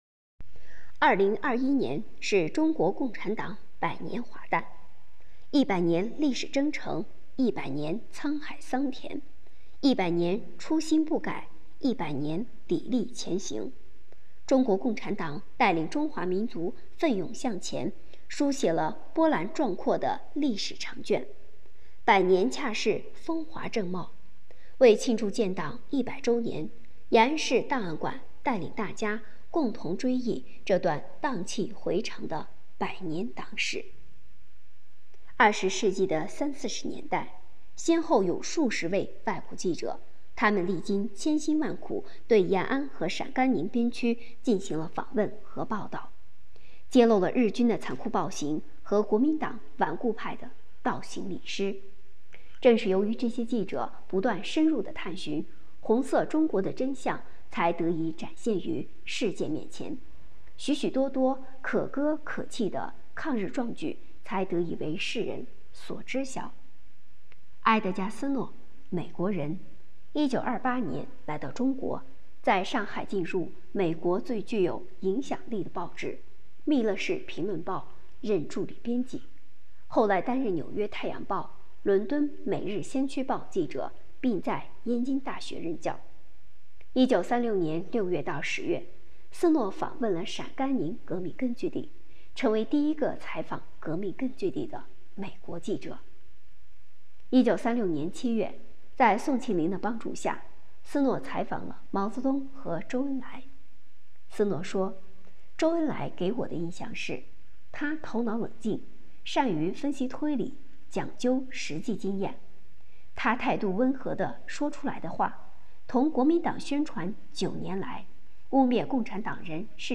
【红色档案诵读展播】第一个采访革命根据地的美国记者埃德加·斯诺